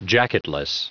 Prononciation du mot jacketless en anglais (fichier audio)